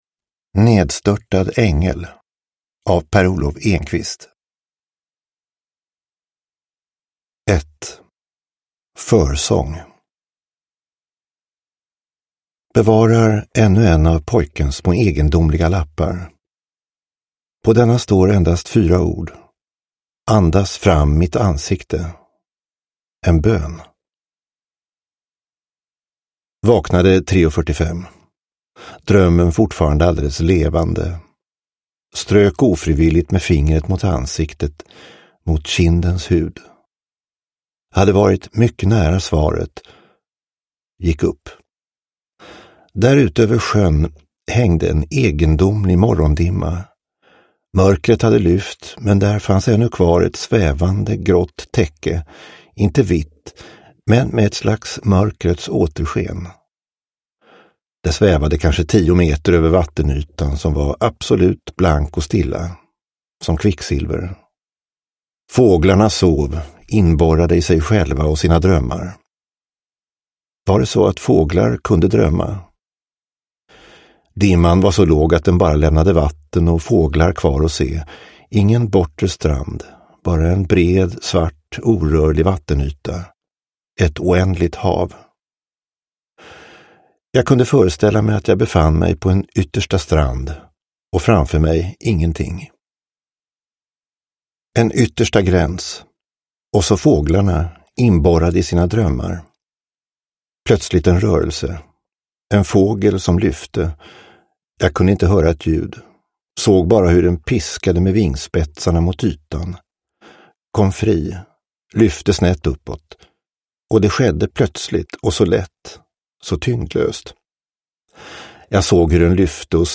Nedstörtad ängel – Ljudbok – Laddas ner